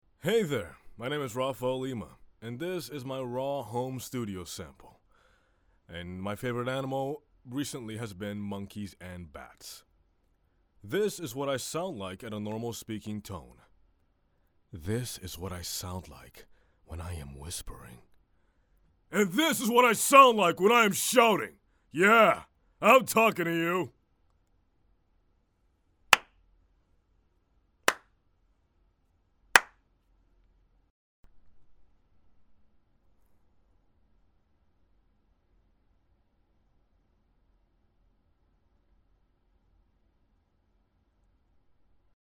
Teenager, Young Adult, Adult, Mature Adult
Has Own Studio
cockney | character
portuguese | natural
smooth/sophisticated